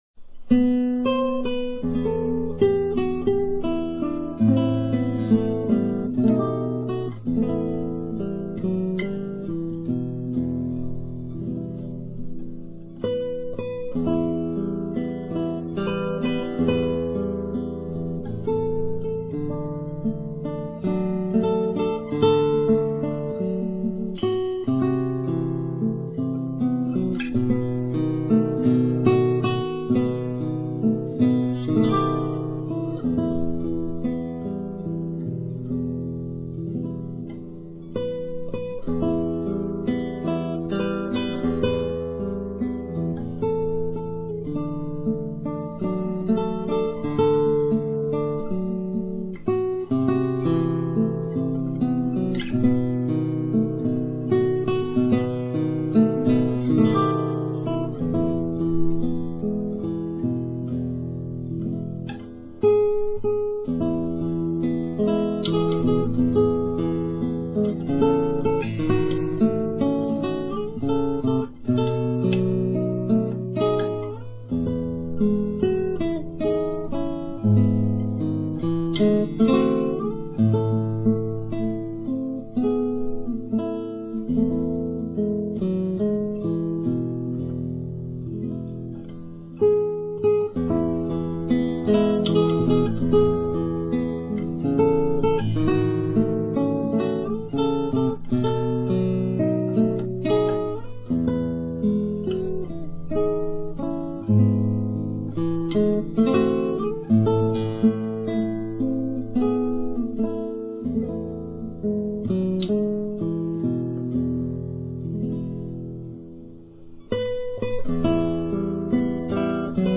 Chuyển soạn cho Guitar